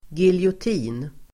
Ladda ner uttalet
giljotin substantiv, guillotine Uttal: [giljåt'i:n] Böjningar: giljotinen, giljotiner Synonymer: fallbila Definition: redskap för avrättning genom halshuggning (instrument for execution by decapitation)